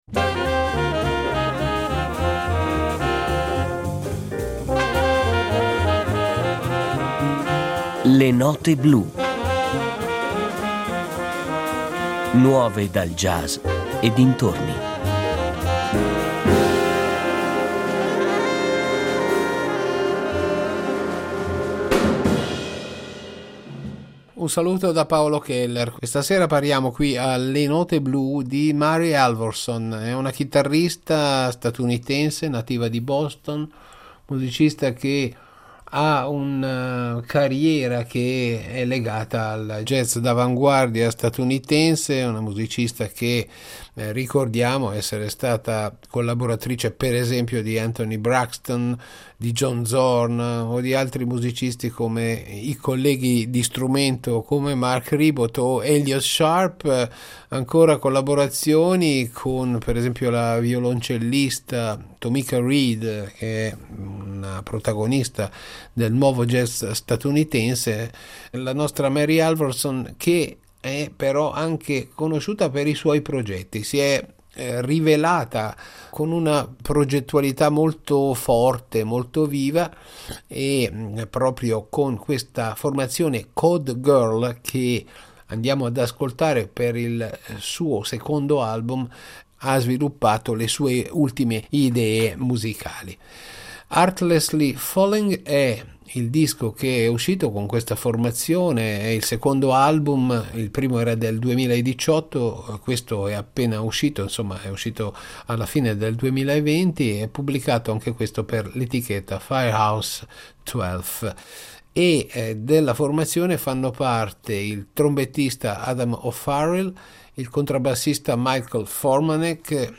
jazz contemporaneo
chitarrista originaria di Boston
tromba
contrabbasso
batteria
sax tenore e voce